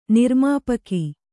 ♪ nirmāpaki